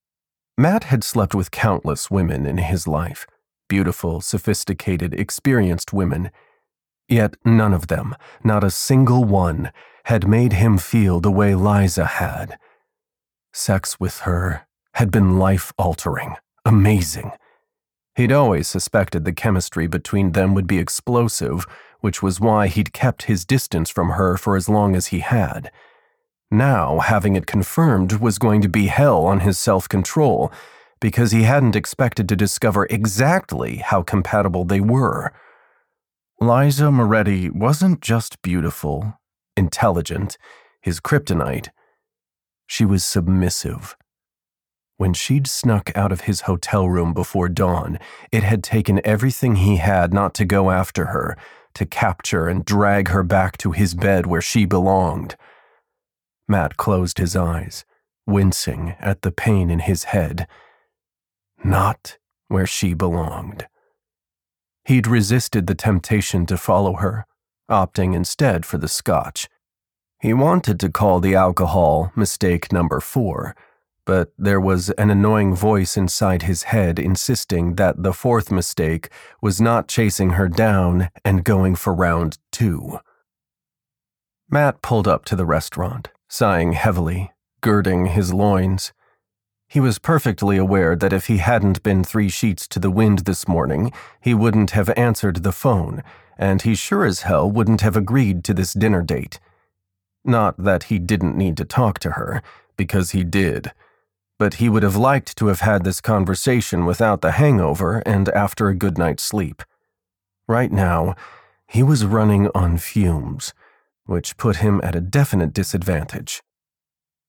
25_PD200_TemptedandTaken_Male-Sample.mp3